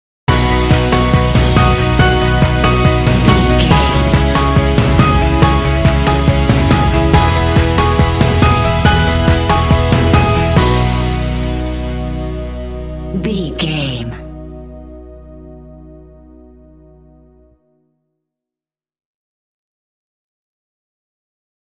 Uplifting
Ionian/Major
driving
joyful
cheerful/happy
electric guitar
bass guitar
drums
percussion
piano
rock
pop
alternative rock
indie